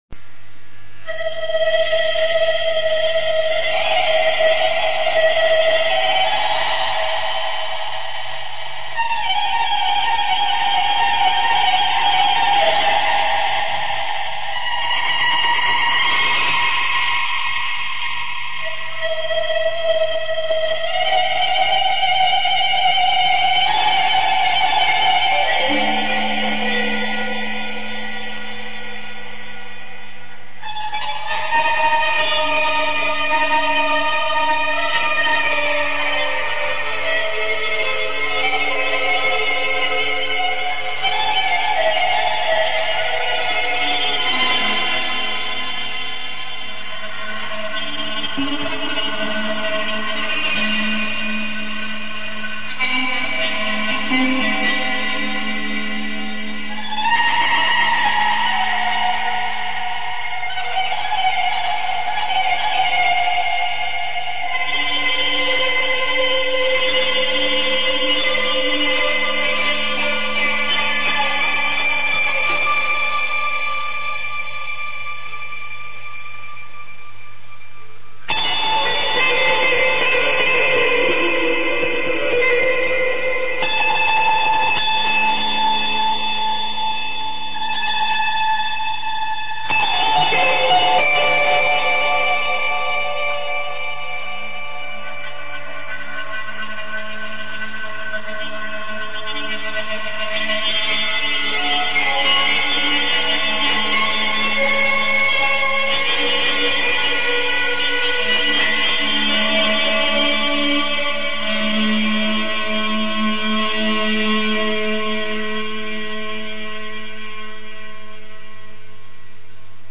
guitar / bow
gitvioli.mp3